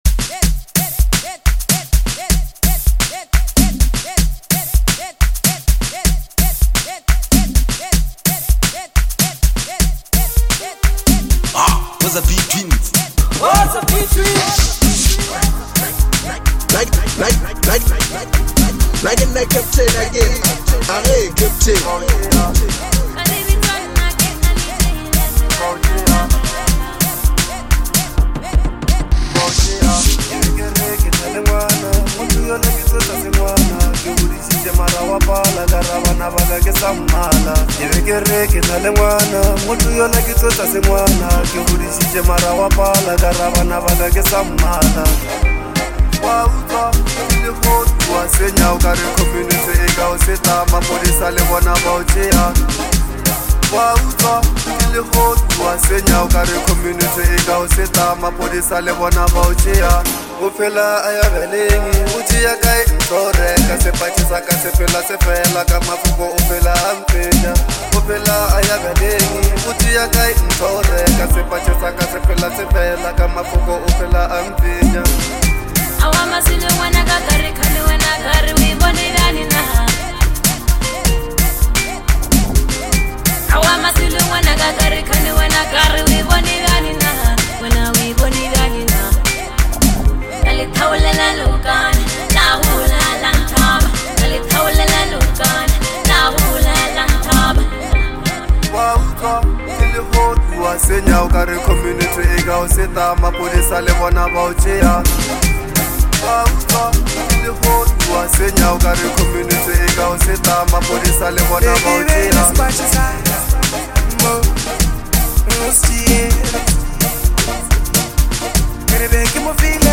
soulful vocals